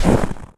STEPS Snow, Run 03.wav